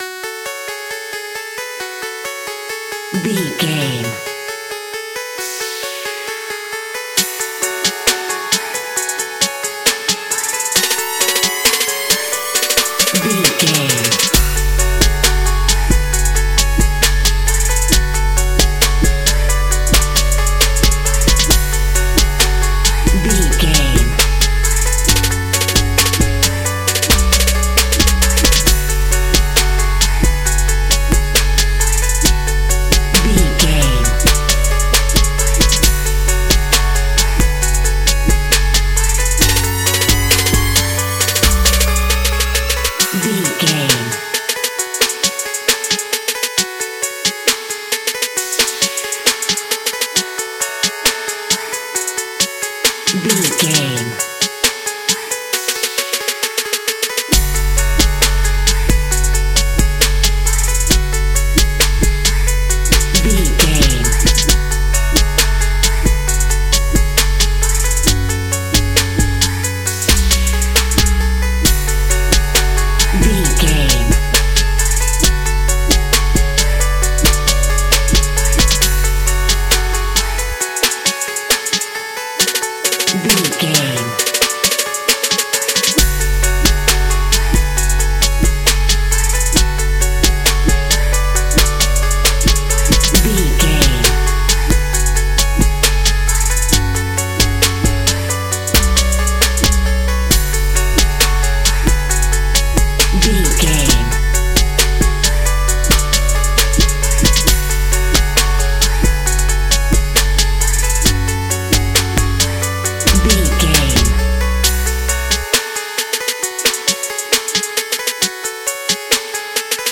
Aeolian/Minor
F#
Funk
synths
synth lead
synth bass
synth drums